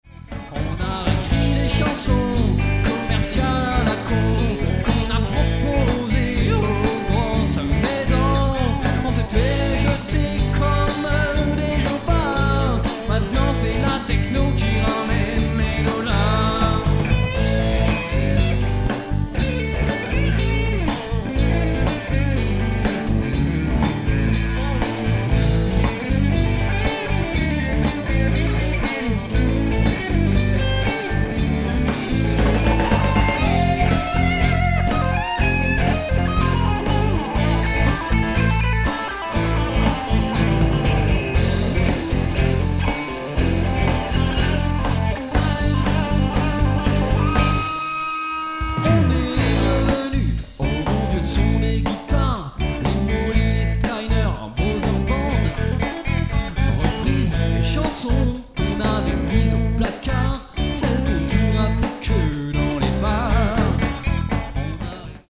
Vieux extraits avec un groupe de Blues/rock (rien à voir avec ce que je fais aujourd'hui, ultra simpliste, mais marrant ) :